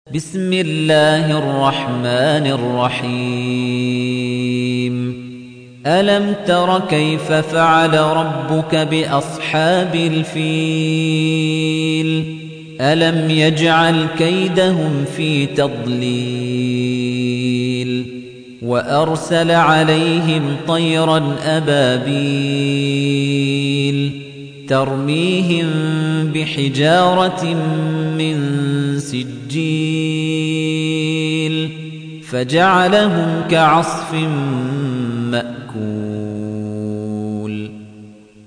تحميل : 105. سورة الفيل / القارئ خليفة الطنيجي / القرآن الكريم / موقع يا حسين